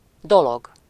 Ääntäminen
France: IPA: /koz/